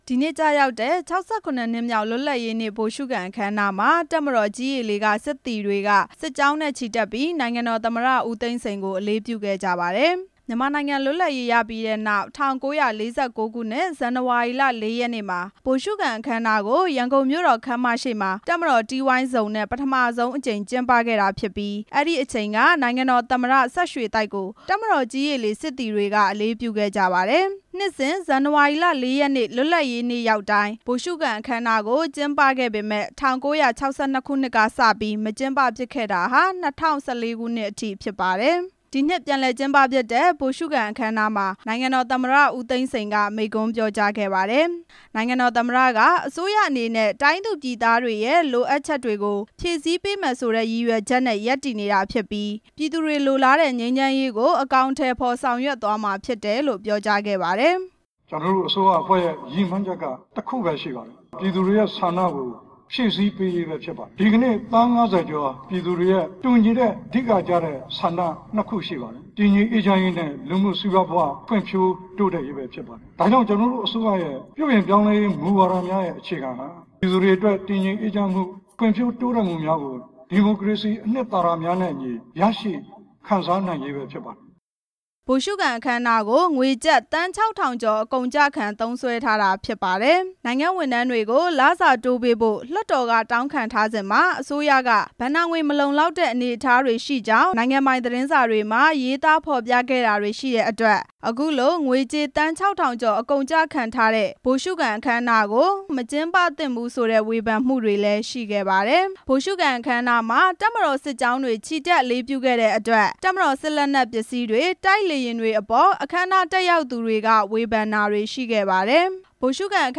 ဒီနှစ်ပြန်လည်ကျင်းပဖြစ်တဲ့ ဗိုလ်ရှုခံအခမ်းအနားမှာ နိုင်ငံတော်သမ္မတဦးသိန်းစိန်က မိန့်ခွန်းပြောကြားခဲ့ပါတယ်။